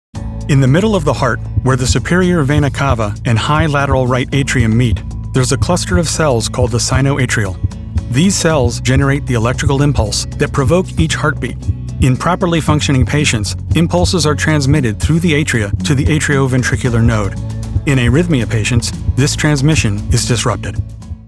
Medical Narration Sample
English - USA and Canada
Middle Aged
Senior